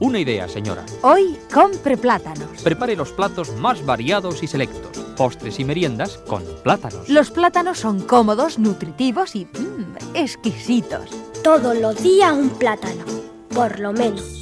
Publicitat "Todos los días un plátano… Por lo menos"